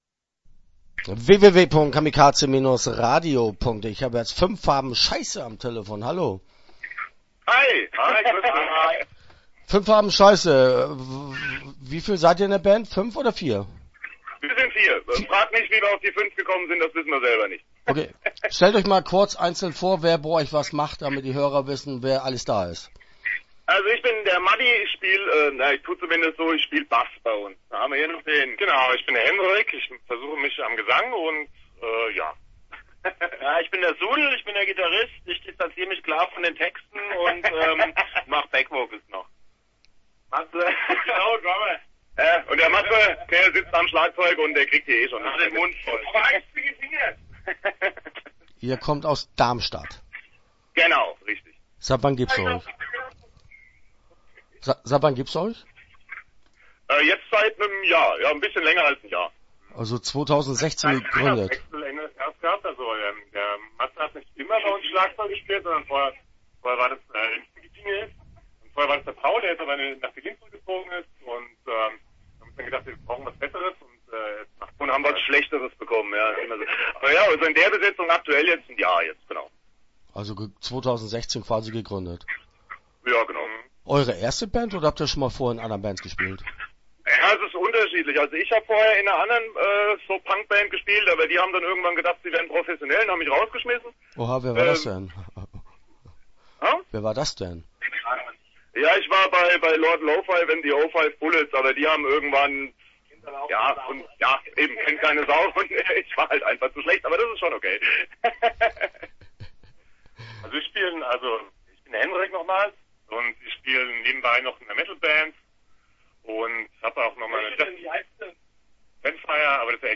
Start » Interviews » 5 Farben Scheisse